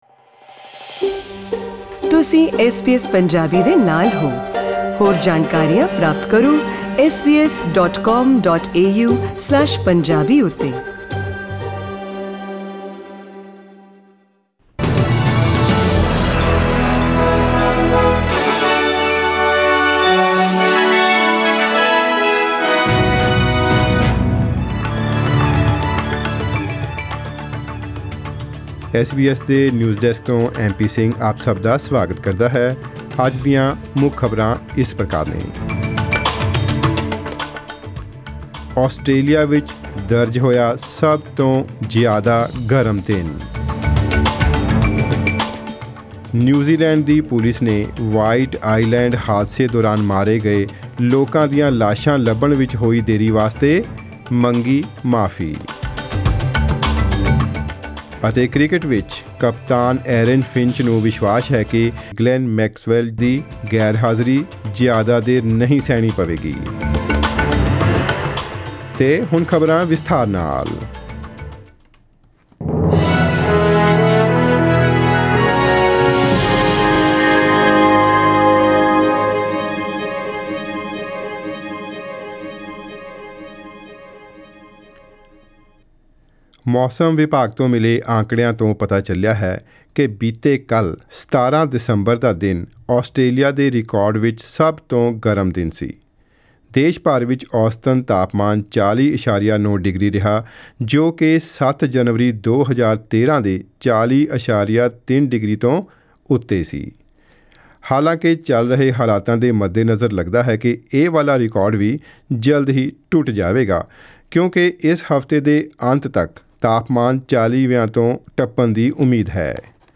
In tonight’s news bulletin – Australia records its hottest day on record; New Zealand police apologise for being unable to find the bodies of two people still missing after the Whakaari / White Island eruption; and In cricket, captain Aaron Finch confident Glenn Maxwell's One Day International absence won't last long.